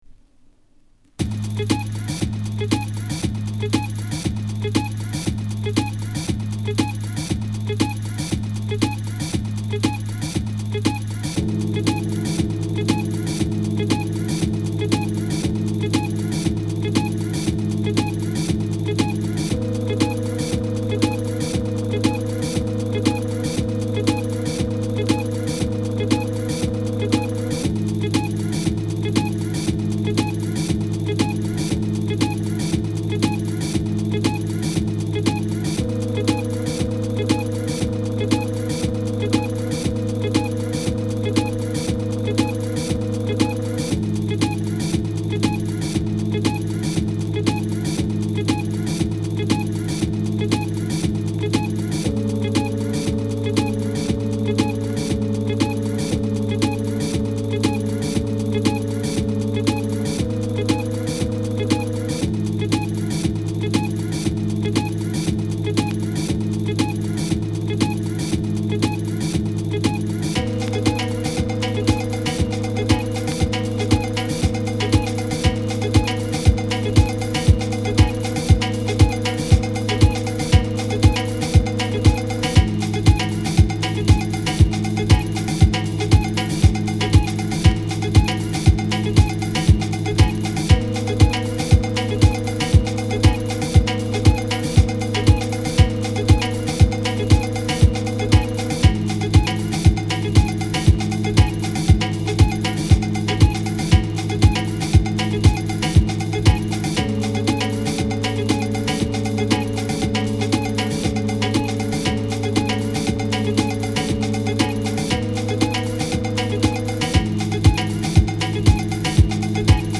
マシーナリーなビートダウン要素も垣間見れるトラックになっています。
House / Techno